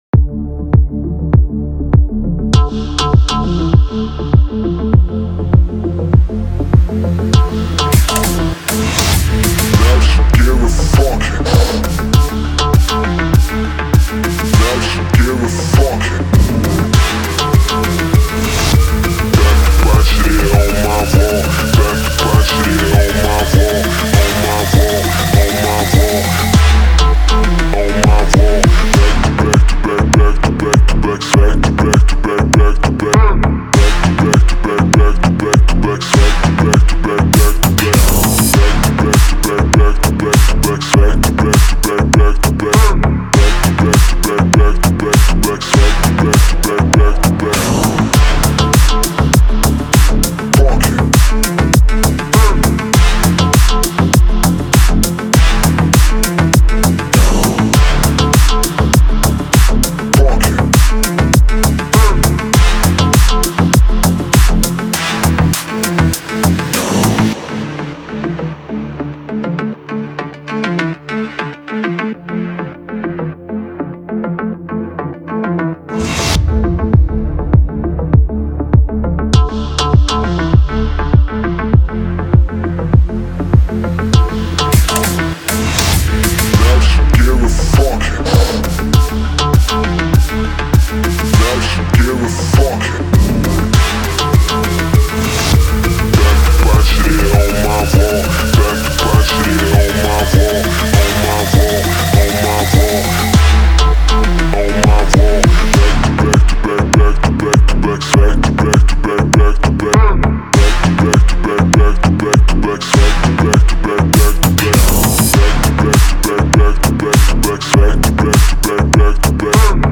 энергичная композиция в жанре EDM